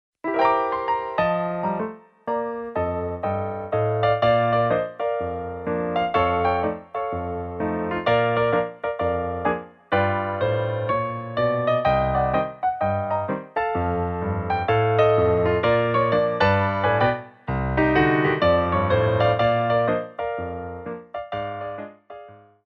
MEDIUM TEMPO